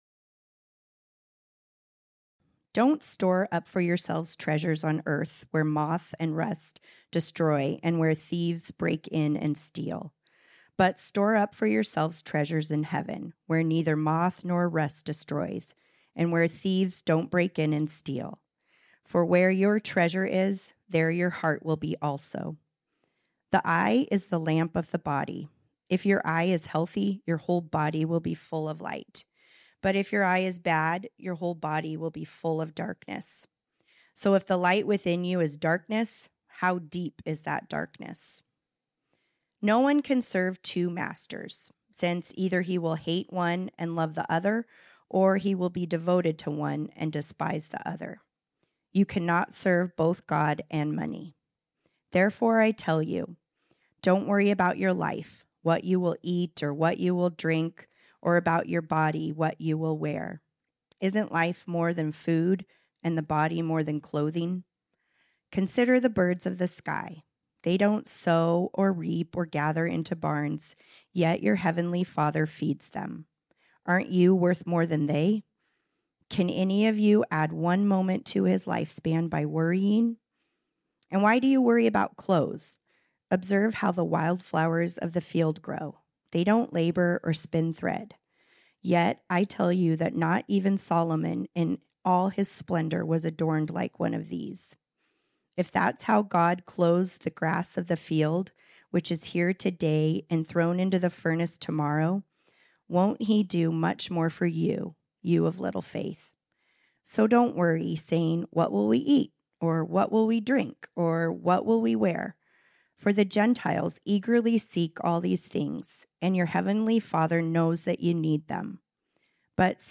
This sermon was originally preached on Sunday, March 3, 2024.